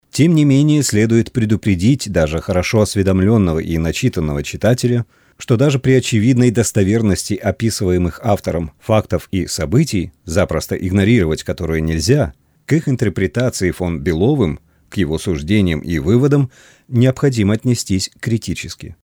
Обладаю мягким, неагрессивным баритоном, подходящим для множества задач.
внешняя звуковая карта M-AUDIO микрофон AUDIO-TECHNICA AT-4033 поп-фильтр, кабинет